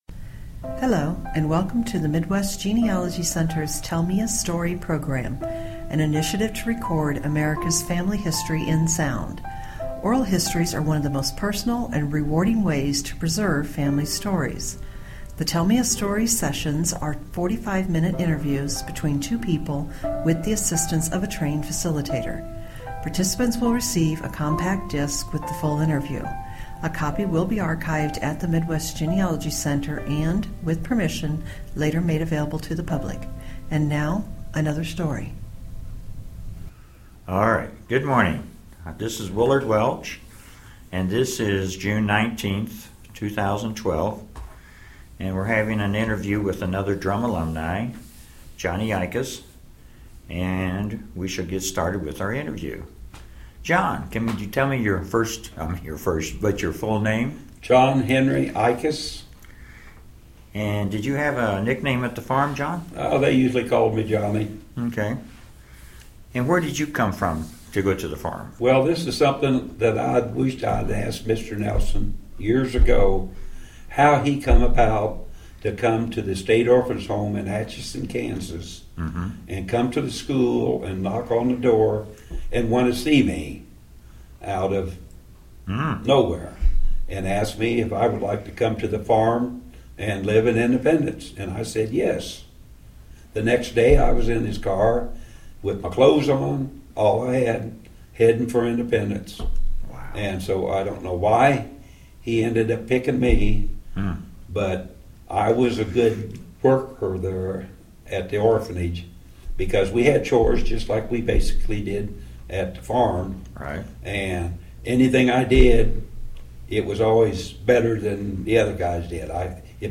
Drumm Institute Oral Histories